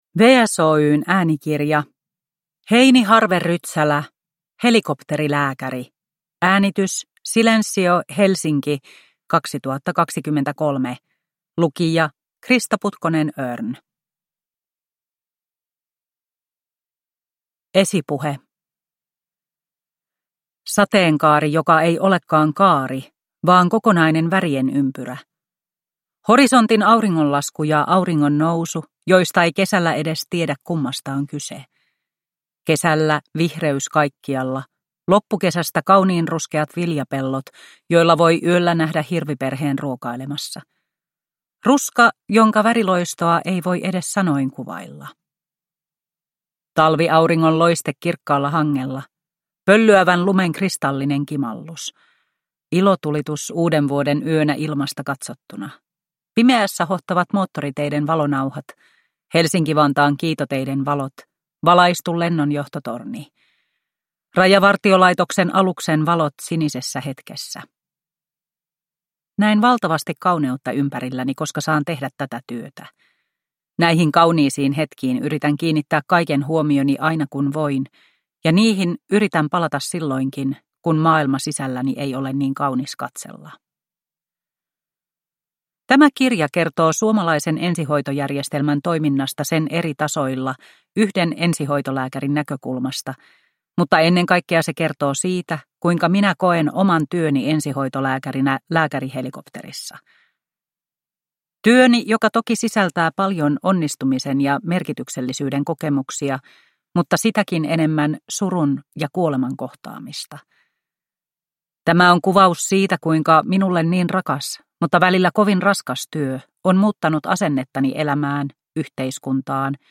Helikopterilääkäri – Ljudbok – Laddas ner